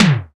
TOM TM008.wav